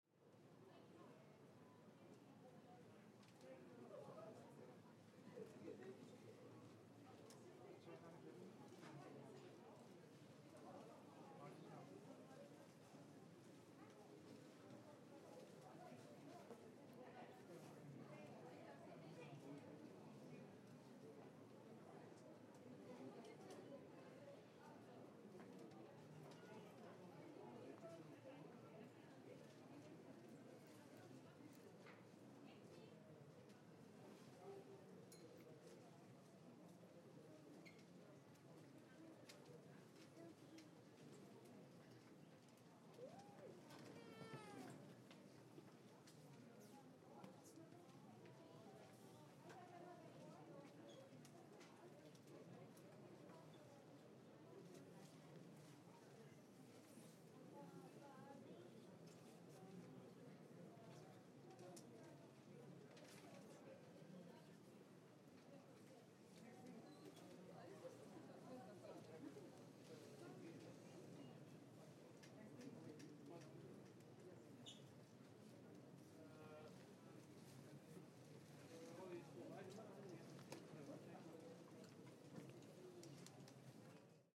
Waiting in line for a Covid-19 test, Malta airport